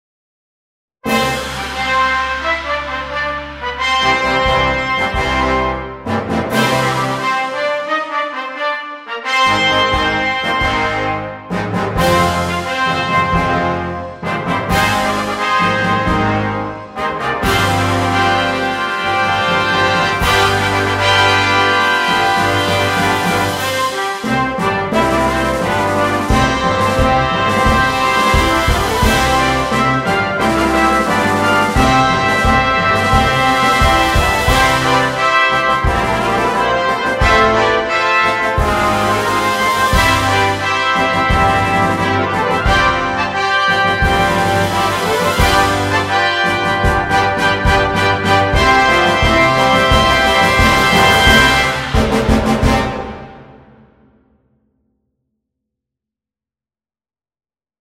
2. Brass Band
Full Band
without solo instrument
Entertainment, Opening Piece